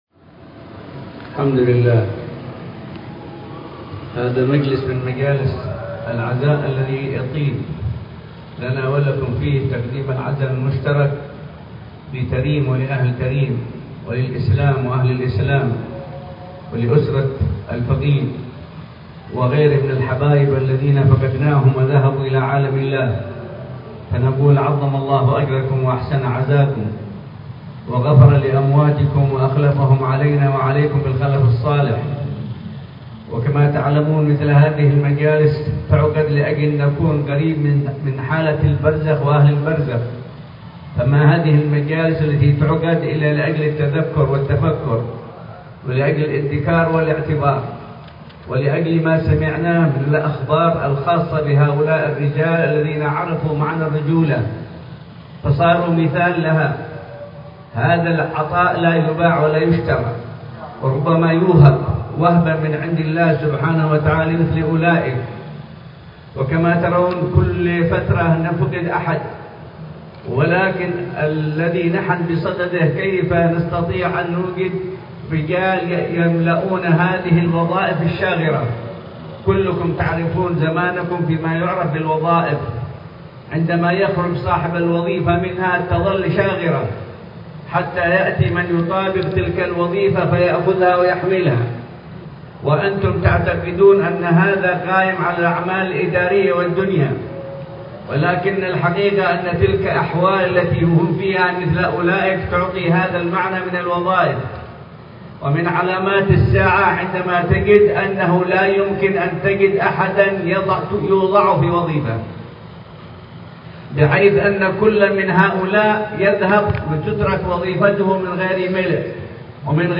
كلمة